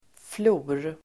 Uttal: [flo:r]